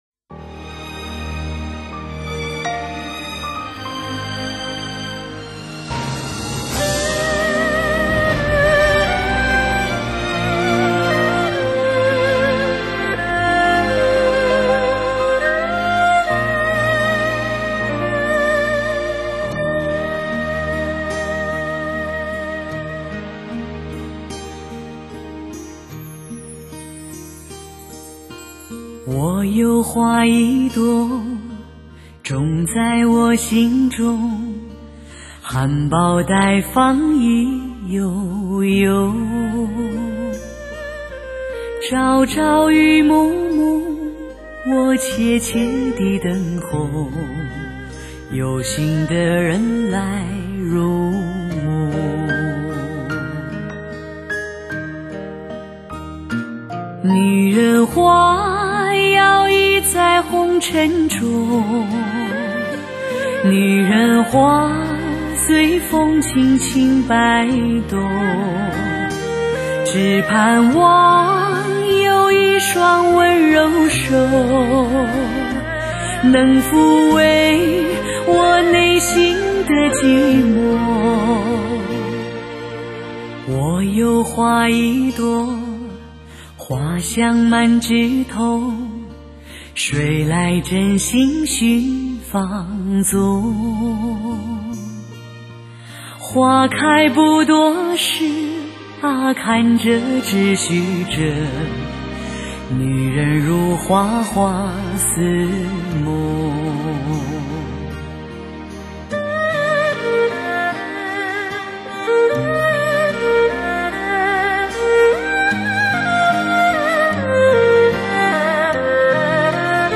首首金曲，混音再造，将录音效果推至完美的境界，
女声与乐器的结像力立体鲜明，细致动人，是试音必备的人声天碟！